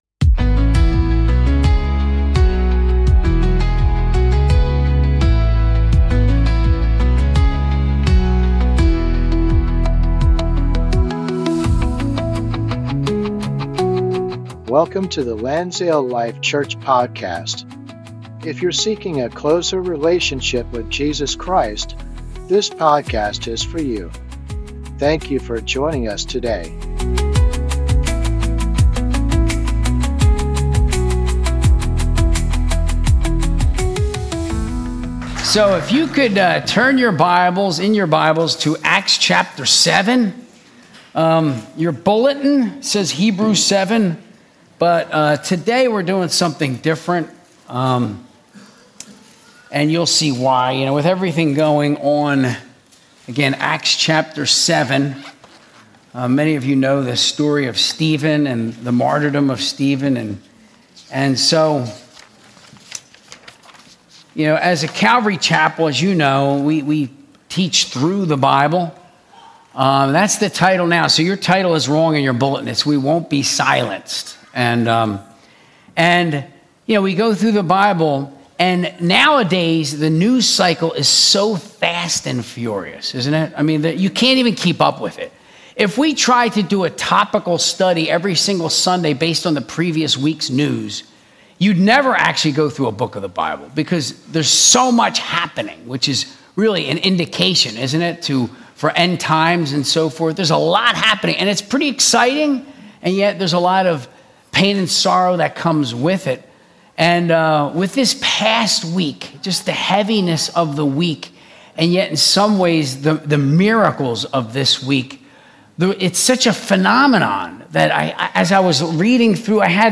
Sunday Service - 2025-09-14